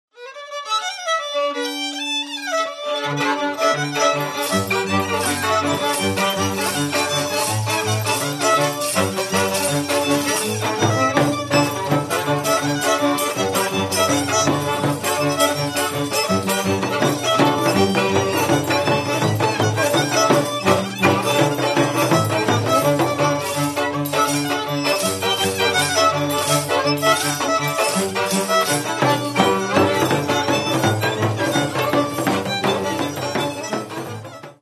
танець